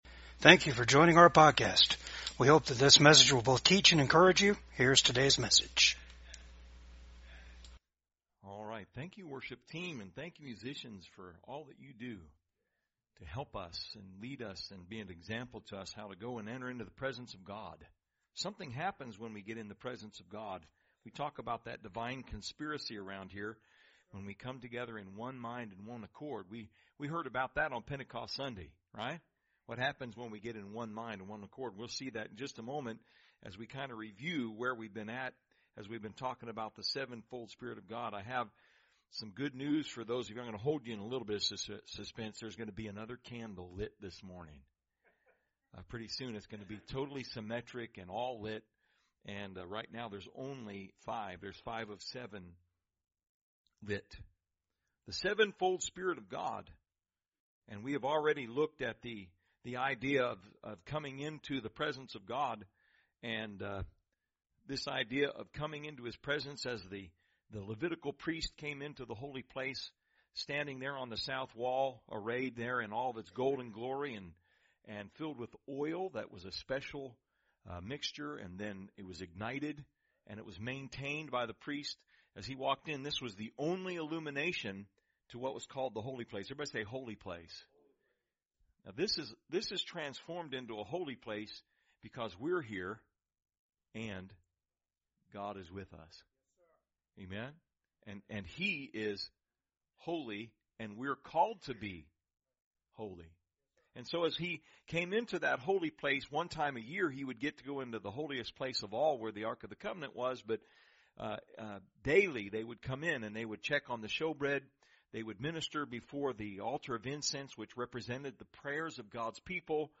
Service Type: VCAG SUNDAY SERVICE GOD ALONE IS RIGHTEOUS.